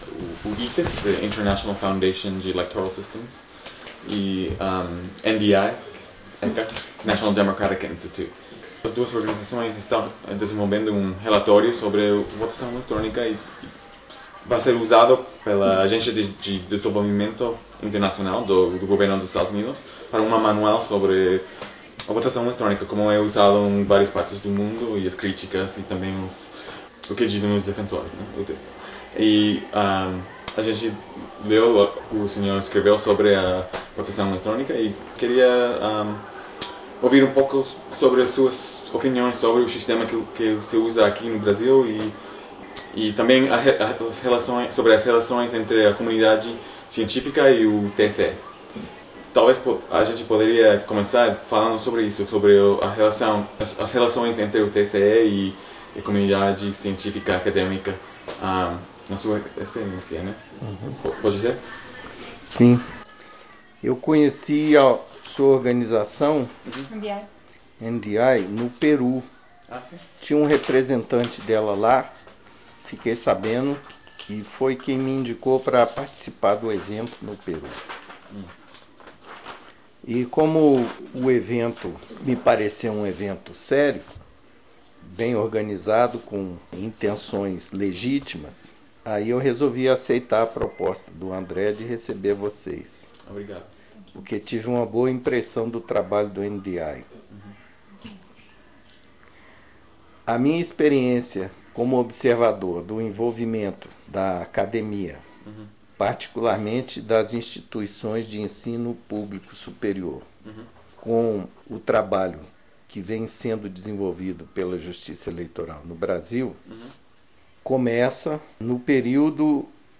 Entrevista à International Foundation of Electoral Systems e ao National Democratic Institute para estudo sobre Experiências no Mundo com Votação Eletrônica.
entrevistaNDI.ogg